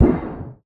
all items in disposal pipes now make small sounds when they hit corners.
clangsmall1.ogg